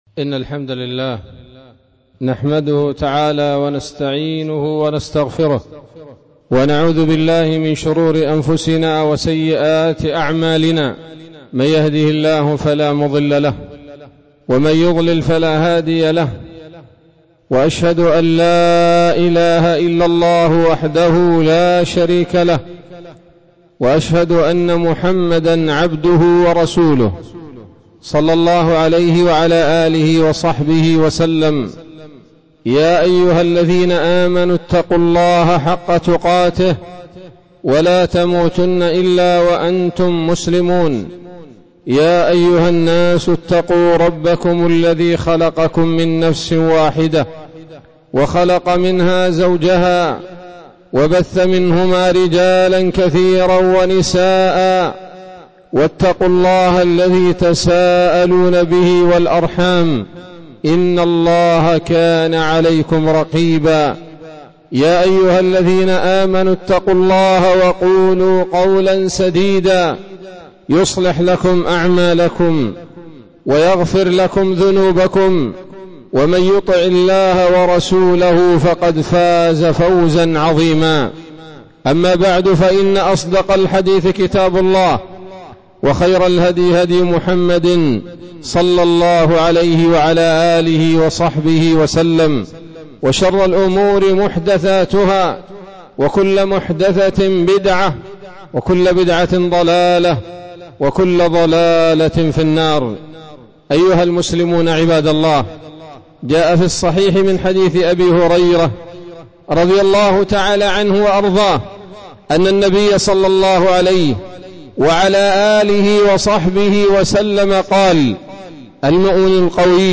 خطبة جمعة بعنوان
17 رجب 1446 هـ، مسجد الصحابة - الغيضة - المهرة